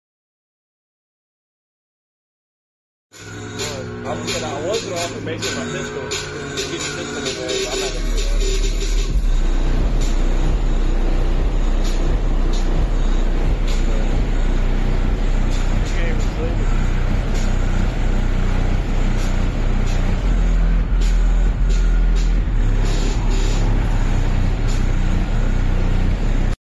Rattling the roof of a buddies shop.